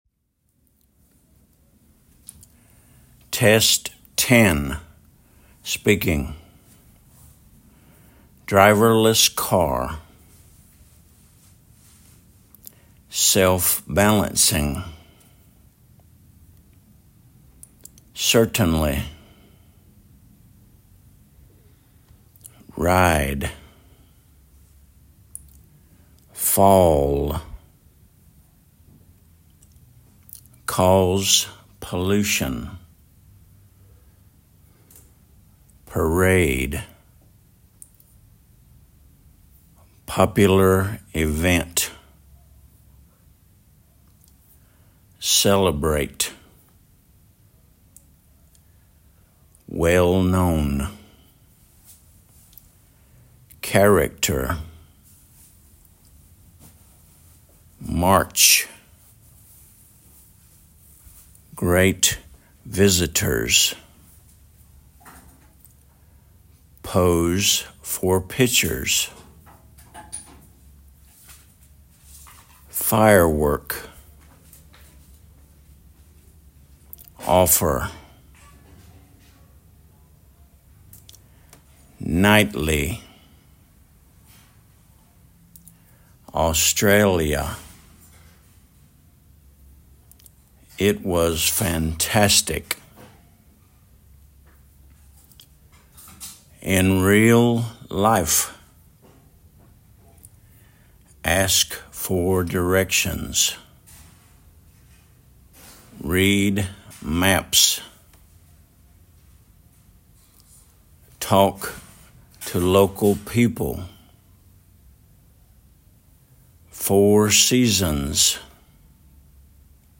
driverless car /ˈdraɪvələs kɑː/
self-balancing /sɛlf ˈbælənsɪŋ/
parade /pəˈreɪd/
It was fantastic /ɪt wəz fænˈtæstɪk/
talk to local people /tɔːk tə ˈləʊkl ˈpiːpl/